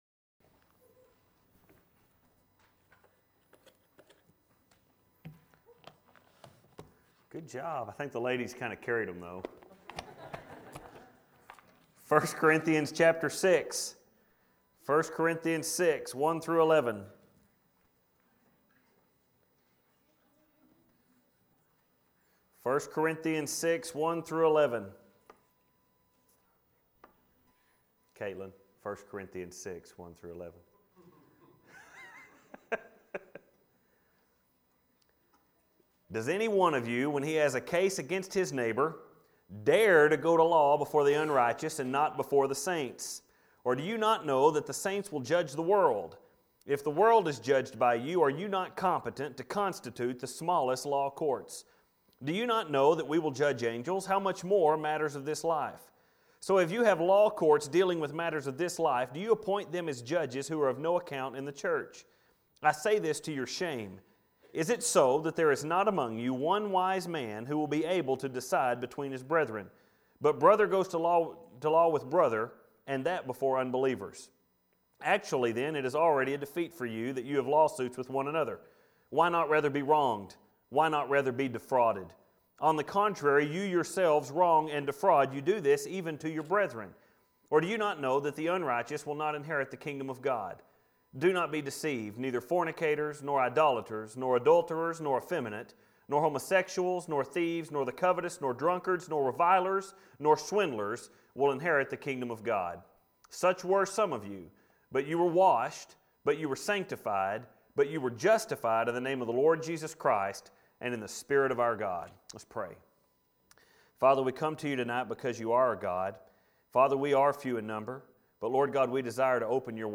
1st Corinthians Dirty Laundry - First Baptist Church Spur, Texas
Filed Under: Sermons Tagged With: Corinthians